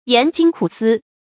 研精苦思 yán jīng kǔ sī
研精苦思发音